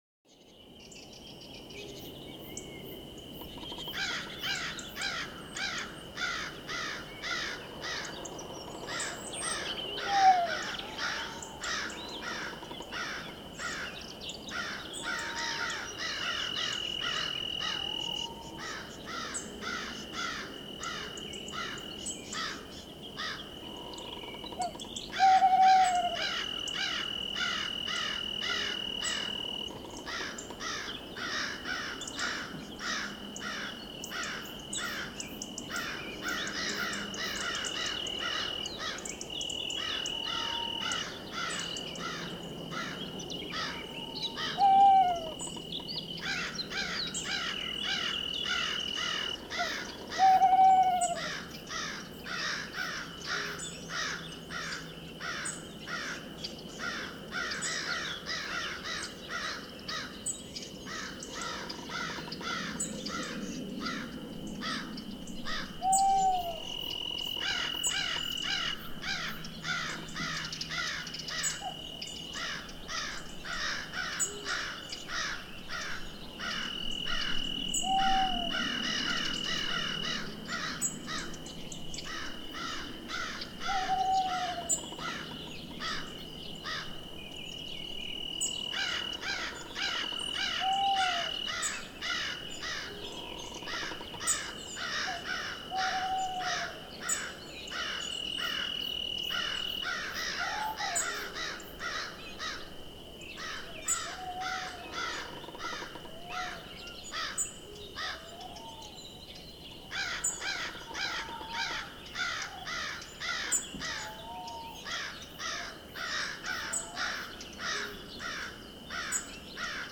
eerie_forest
Category 🌿 Nature
ambience atmosphere birds creak crickets crows eerie forest sound effect free sound royalty free Nature